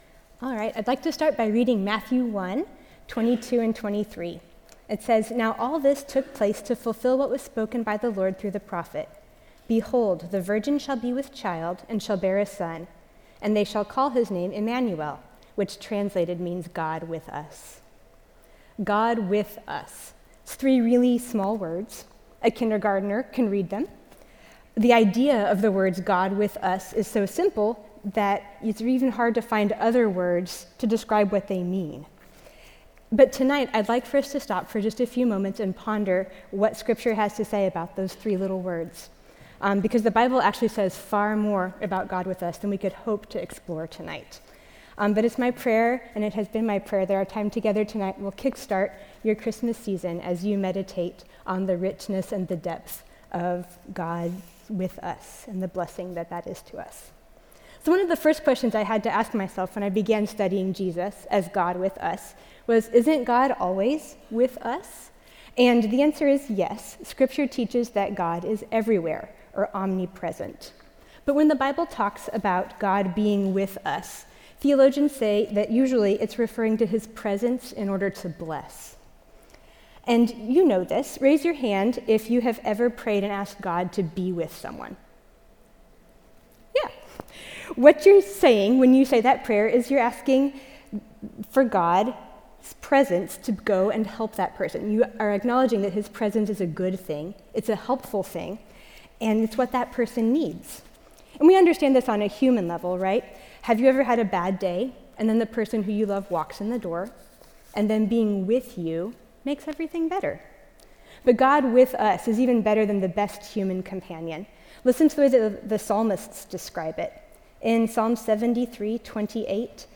Women Women's Christmas Banquets Audio ◀ Prev Series List Previous 5.